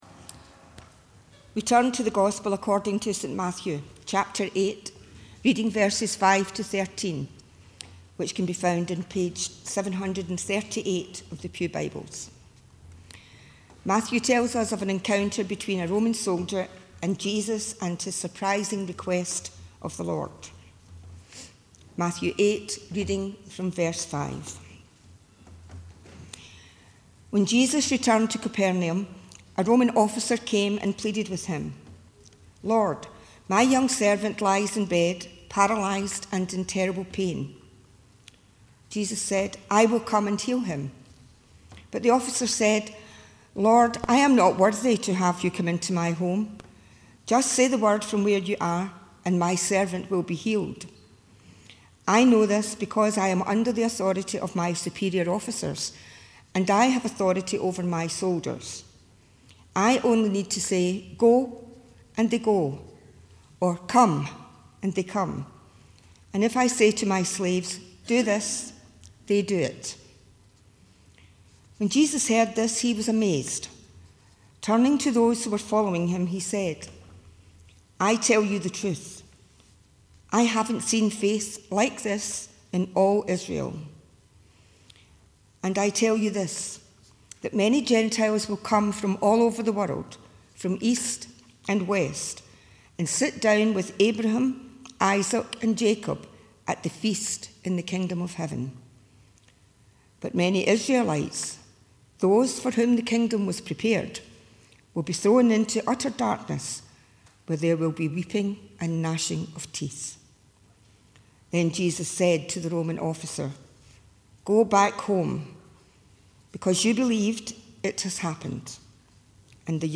The reading prior to the sermon is Matthew 8: 5-13